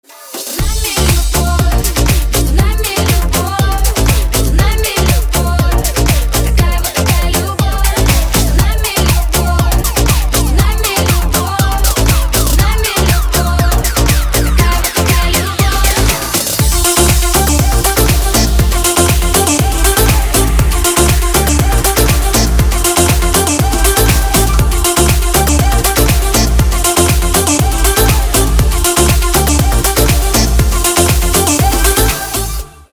• Качество: 320, Stereo
женский вокал
dance
Club House